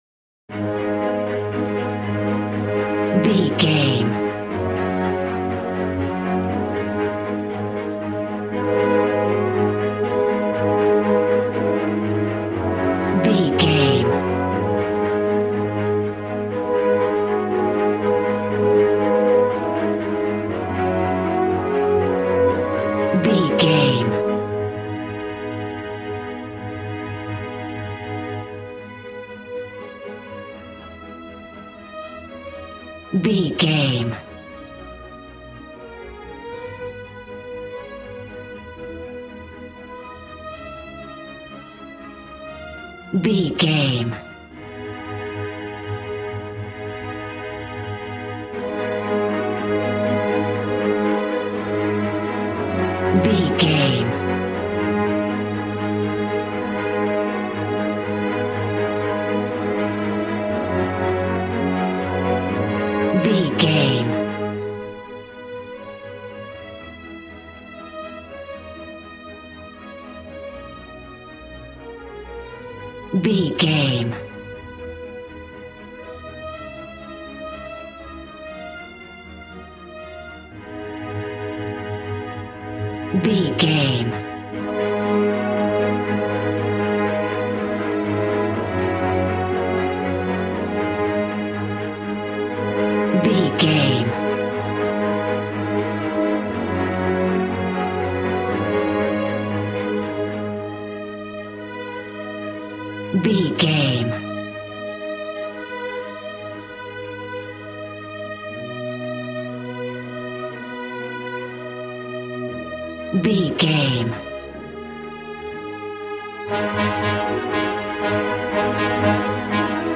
A classical music mood from the orchestra.
Regal and romantic, a classy piece of classical music.
Aeolian/Minor
A♭
regal
cello
violin
strings